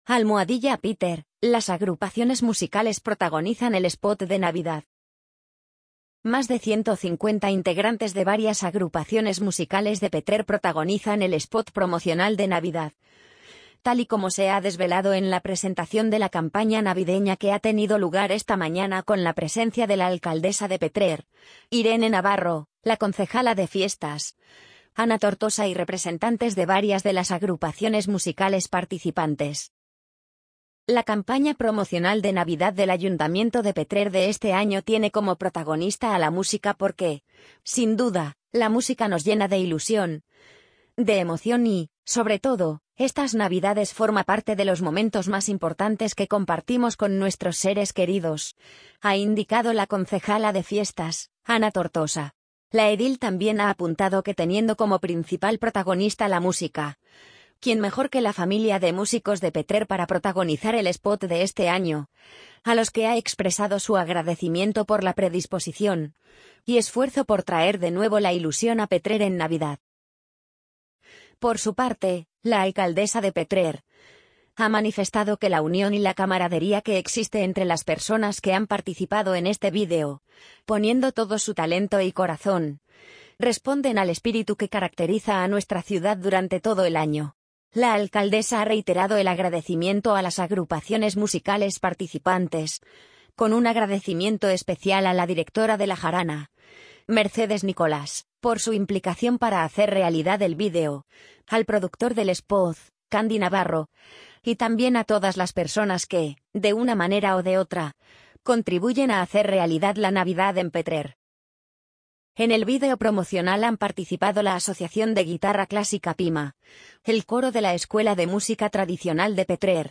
amazon_polly_39196.mp3